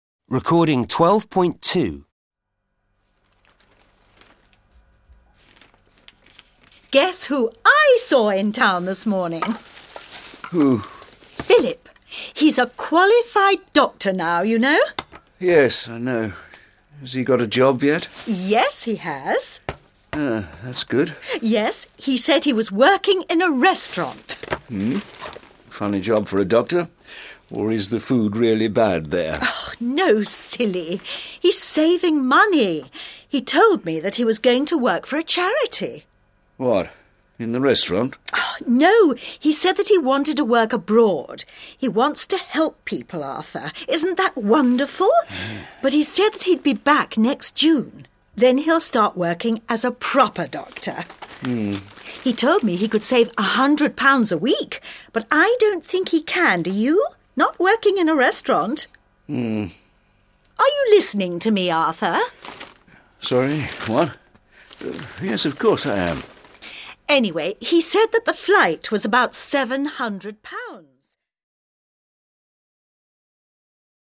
conversation_B.mp3